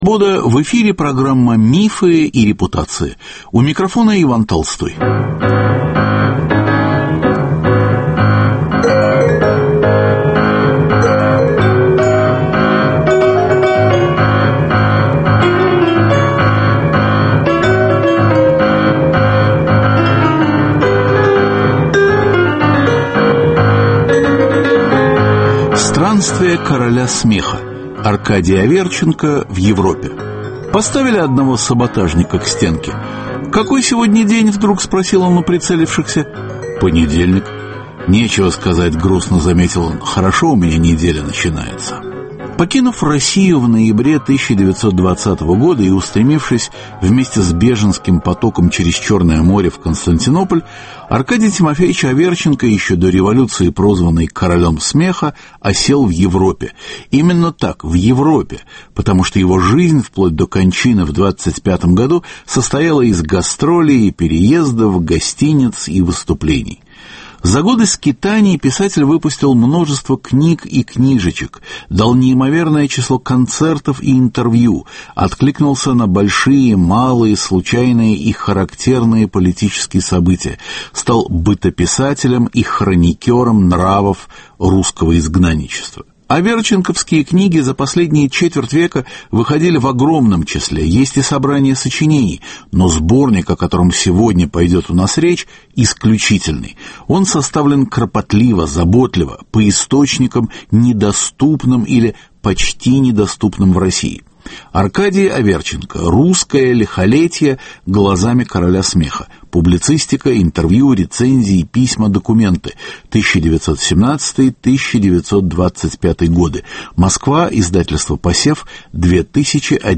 Звучат аверченковские миниатюры.